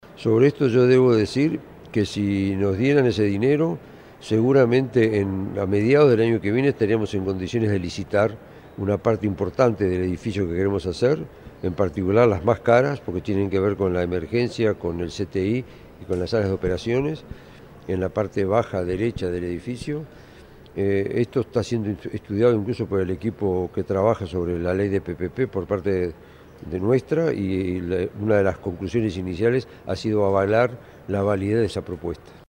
Lo explicó Markarian en rueda de prensa: